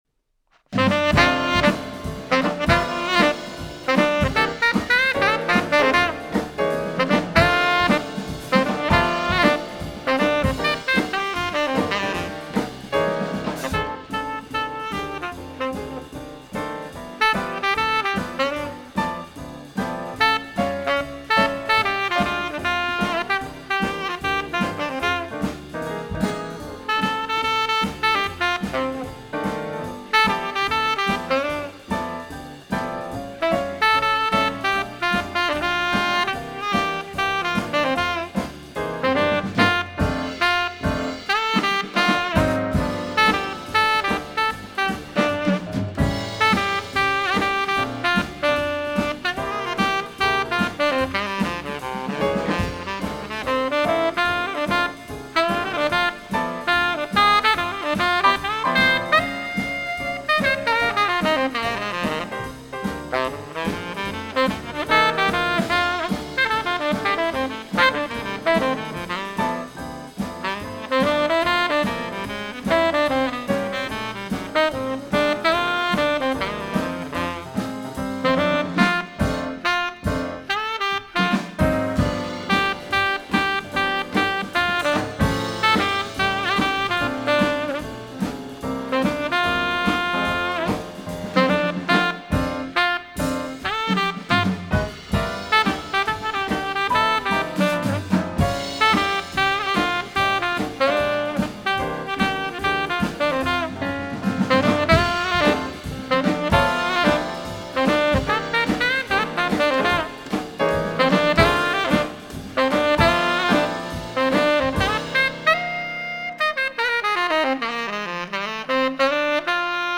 Genre Jazz
Tenorsax
Piano
Drums